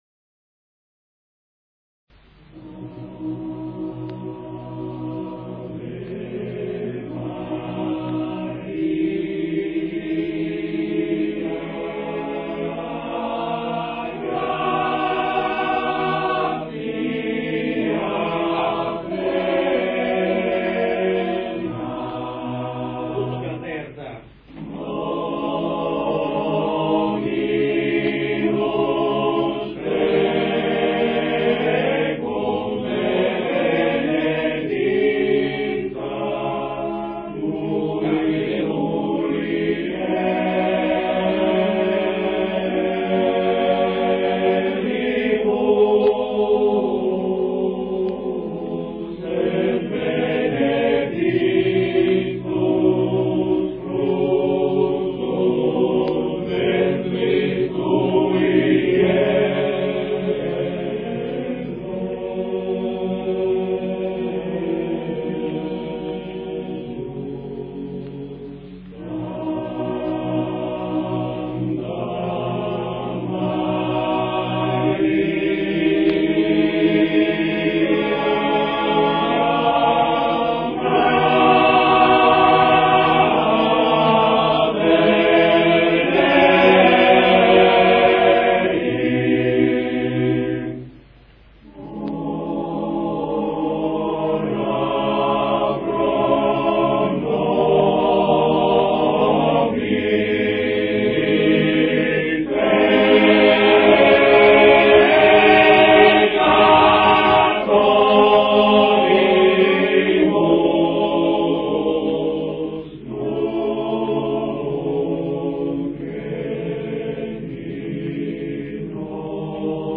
Ricerca, elaborazione, esecuzione di canti popolari emiliani
voci virili